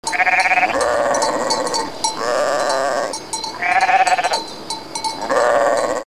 Звуки баранов, овец
Звук барана з колокольчиком